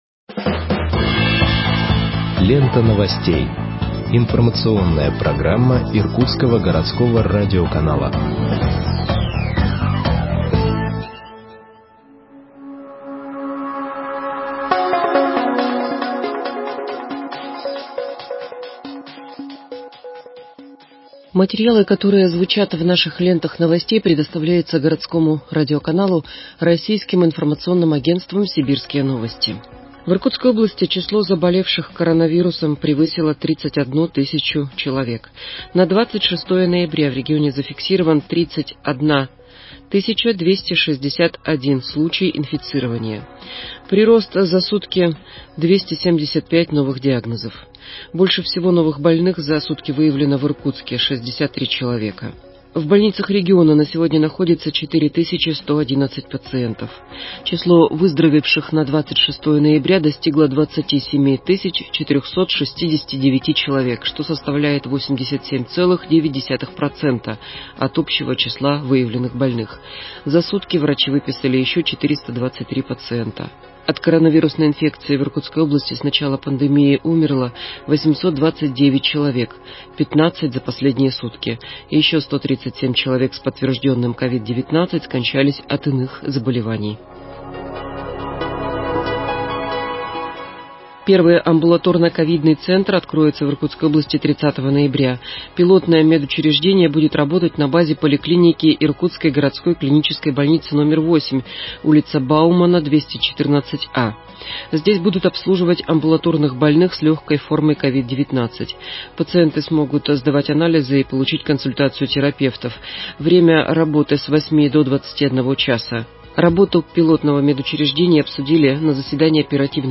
Выпуск новостей в подкастах газеты Иркутск от 27.11.2020 № 2